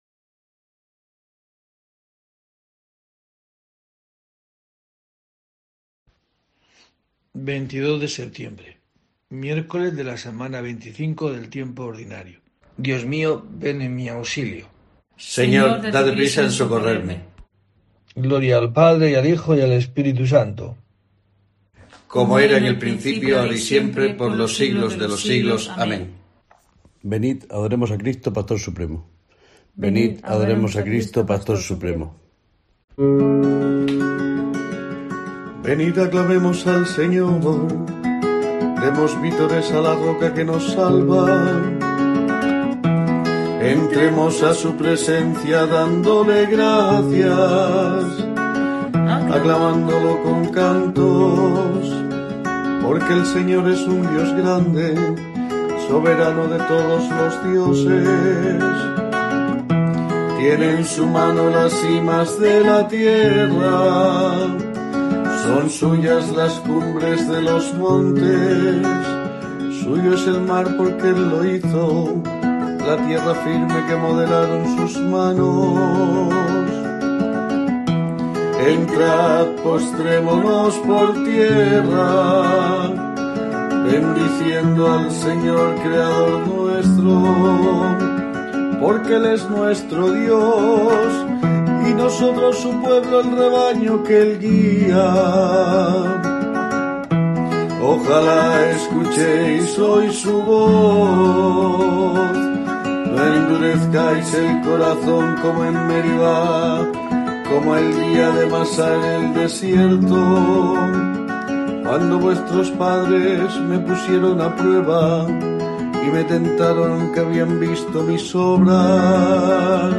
22 de septiembre: COPE te trae el rezo diario de los Laudes para acompañarte